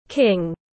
Vua tiếng anh gọi là king, phiên âm tiếng anh đọc là /kɪŋ/.
King /kɪŋ/